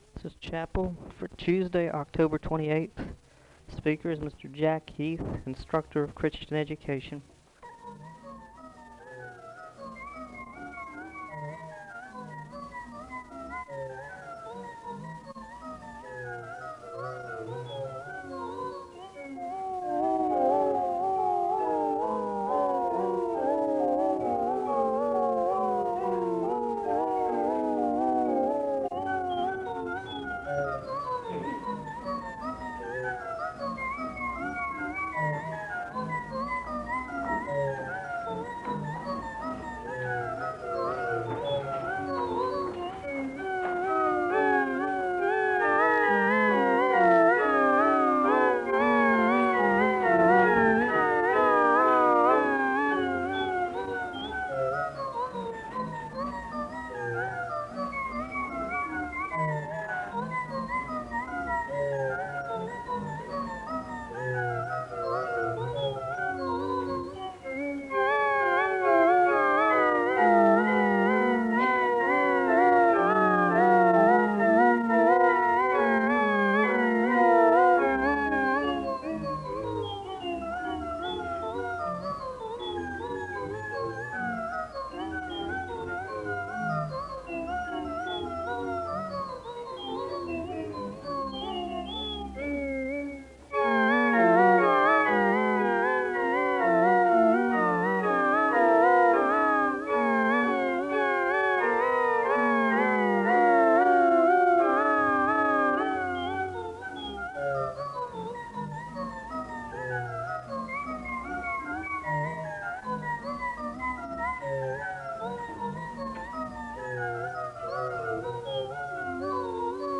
The service begins with organ music (0:00-2:06).
Prayer concerns are shared with the congregation (2:35-3:28). The congregation is led in a hymn for prayerful thought (3:29-4:44). There are two songs of worship (4:45-11:02). There is a responsive reading (11:03-12:38). The choir sings a song of worship (12:39-16:36). There are solo songs of worship (16:36-22:40).
Location Wake Forest (N.C.)